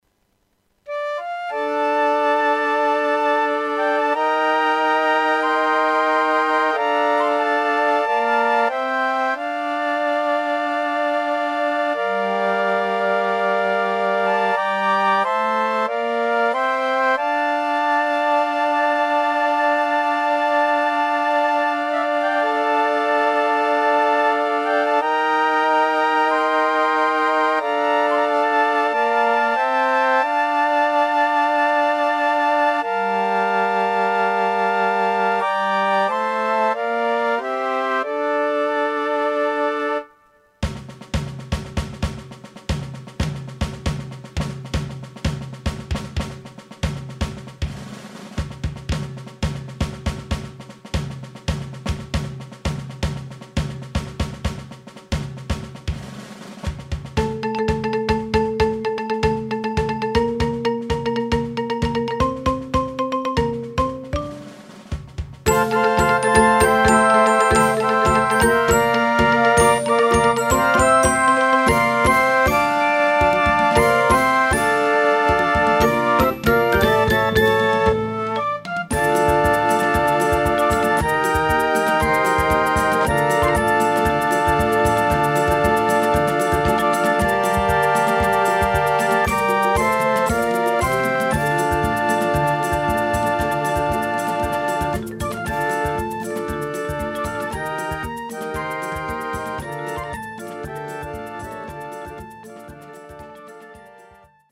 Fluiterkorps
Demo